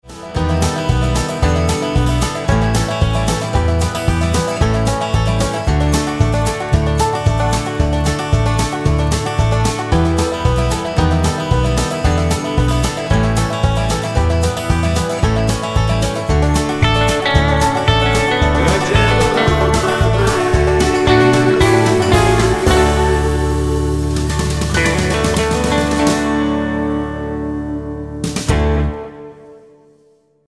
Tonart:D mit Chor